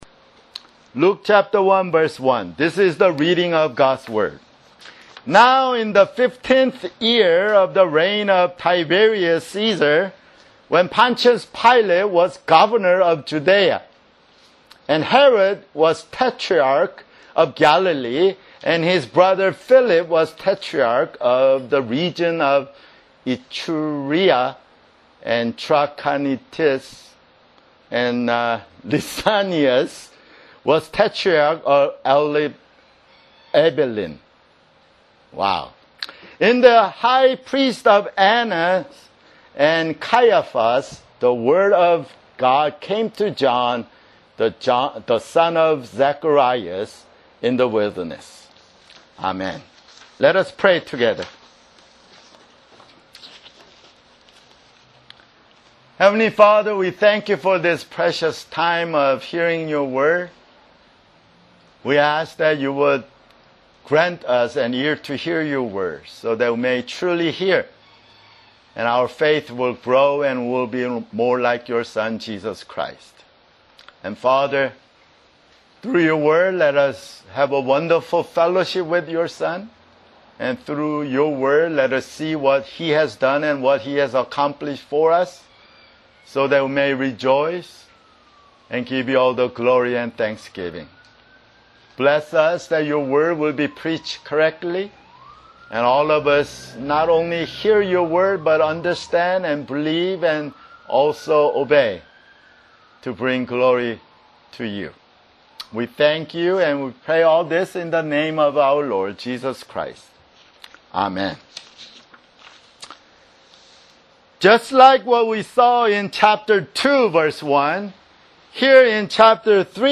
[Sermon] Luke (21)